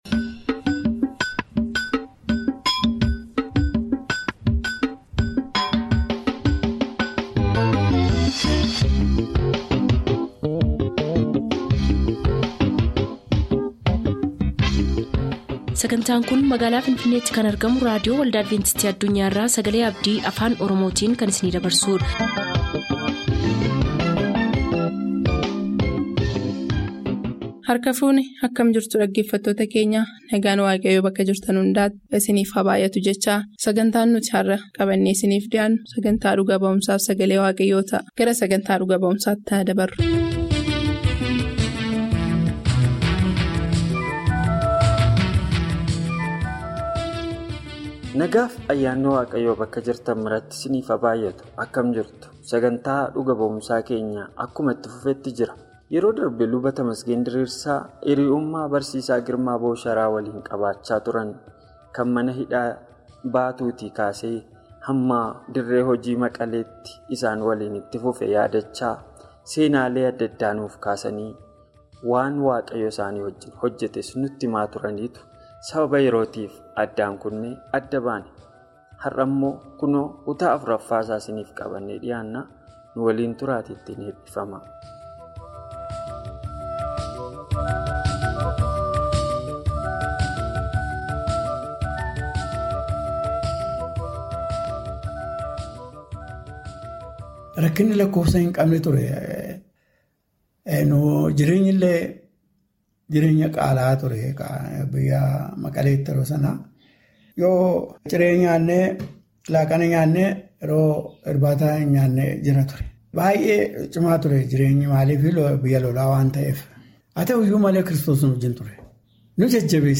MUUXANNOO JIREENYYAA FI LALLABA. WITTNESING AND TODAY’S SERMON